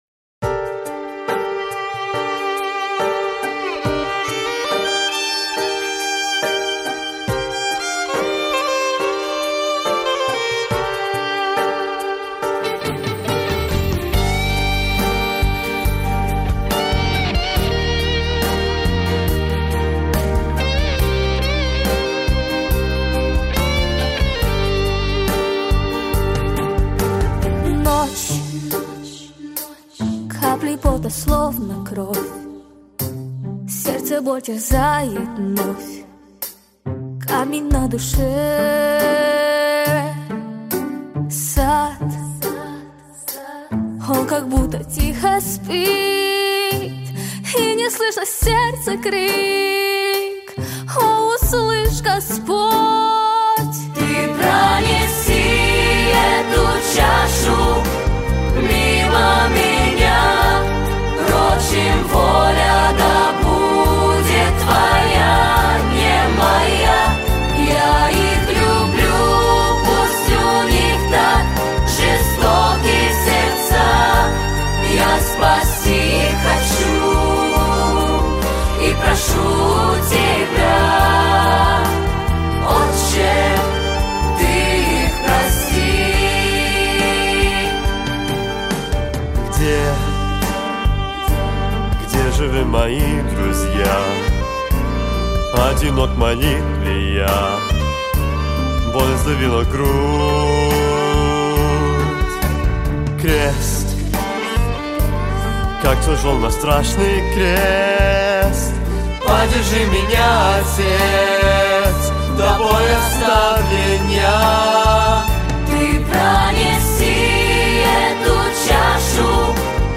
• Жанр: Детские песни
христианские песни